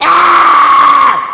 Smoker_Warn_03.wav